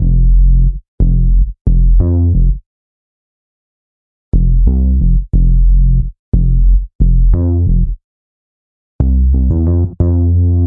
chillout " Winter Bass 4
描述：爵士乐，音乐，爵士乐